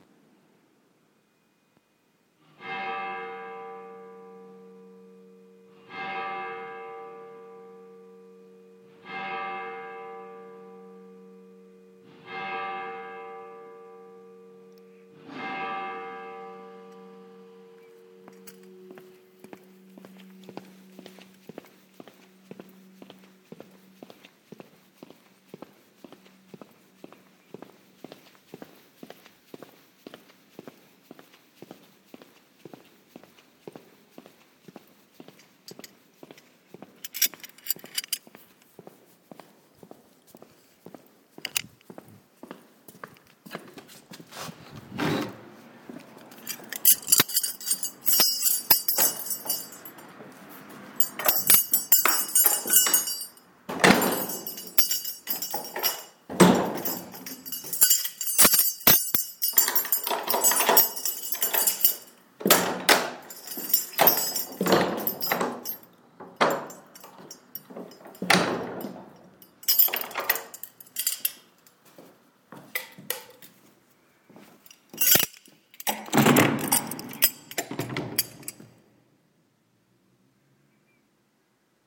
The bells strike five o'clock and I lock the door.